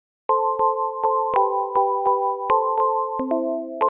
Sample sounds, mostly quite short